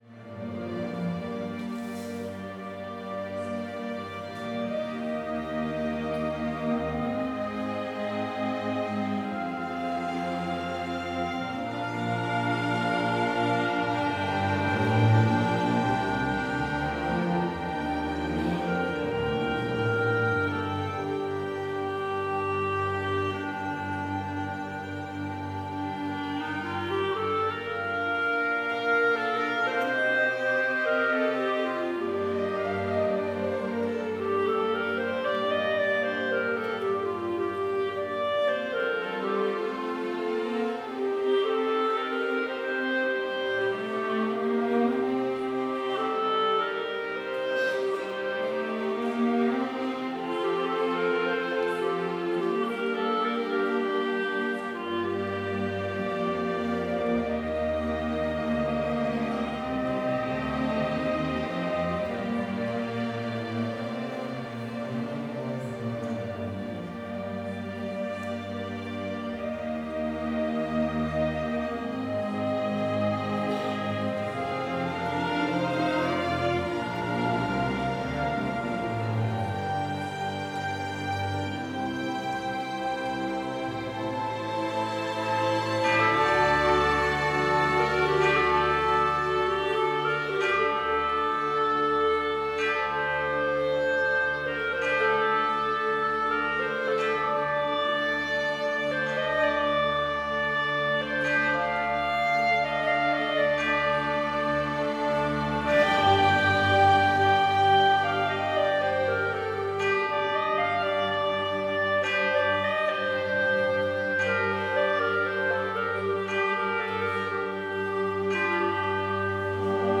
Complete service audio for Chapel - Friday, February 21, 2025